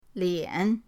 lian3.mp3